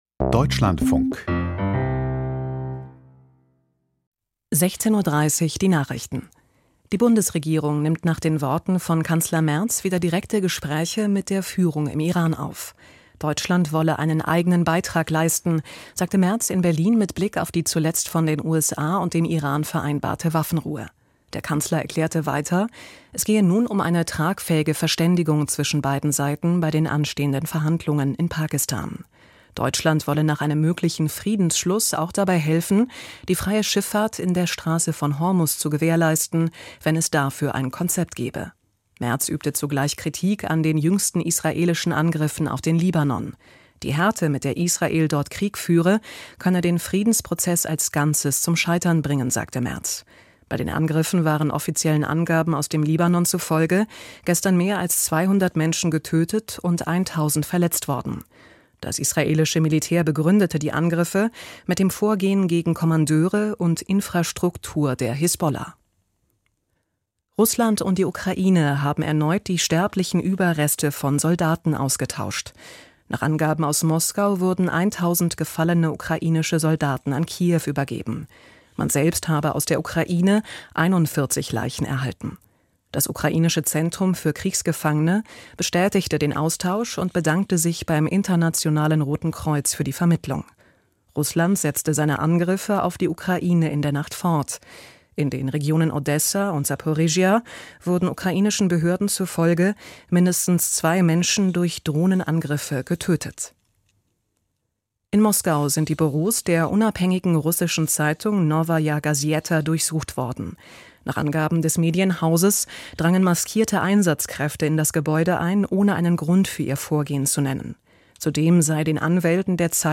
Die Nachrichten vom 09.04.2026, 16:30 Uhr
Die wichtigsten Nachrichten aus Deutschland und der Welt.